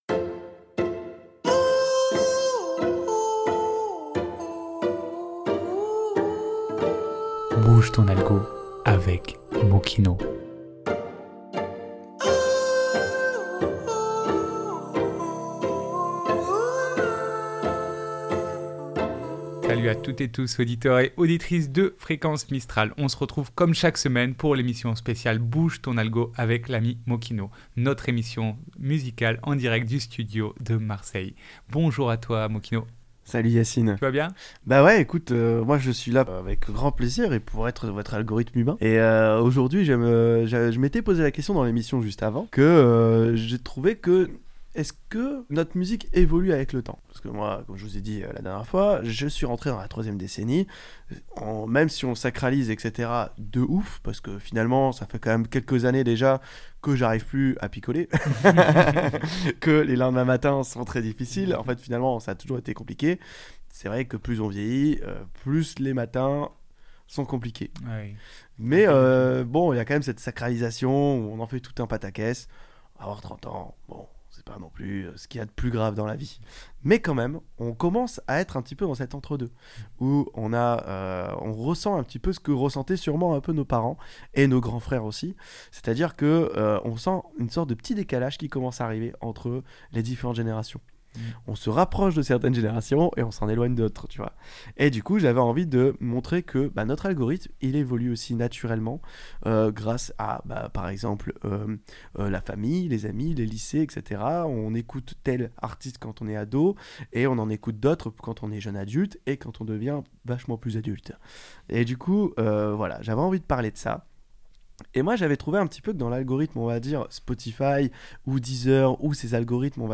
Rencontre avec l'artiste